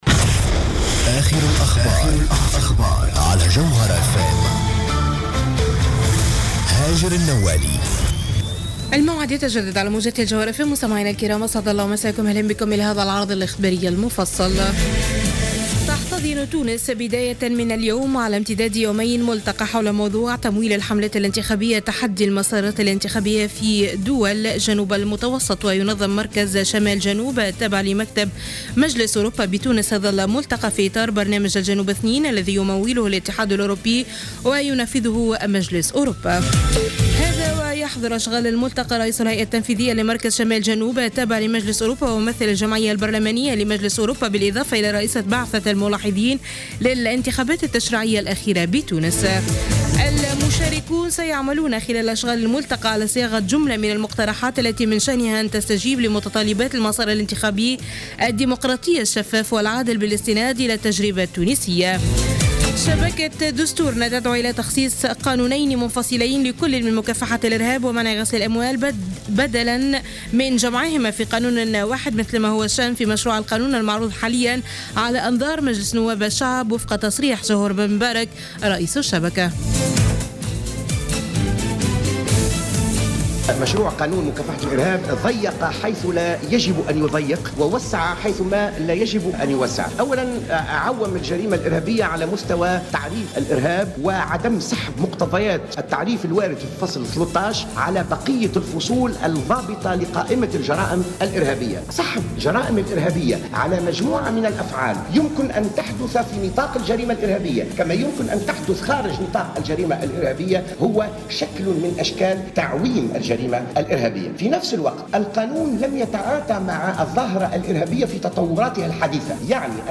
نشرة أخبار منتصف الليل ليوم الاثنين 27 أفريل 2015